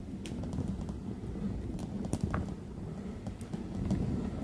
droneWheeling.wav